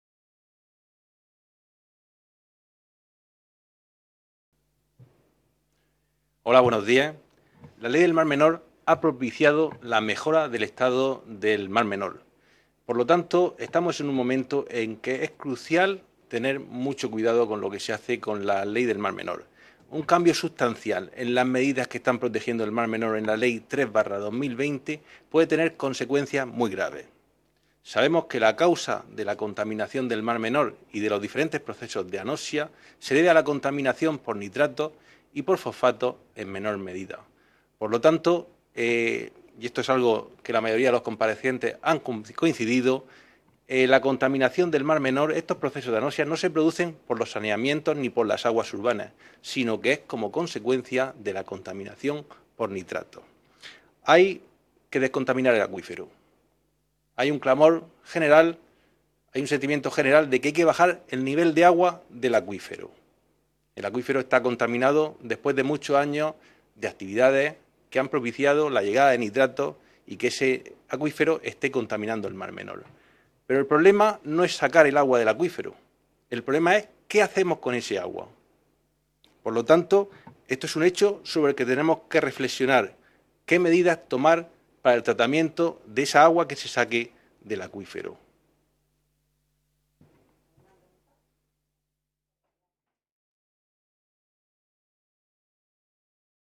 Ruedas de prensa tras la Comisión de Asuntos Generales e Institucionales, de la Unión Europea y Derechos Humanos
• Grupo Parlamentario Socialista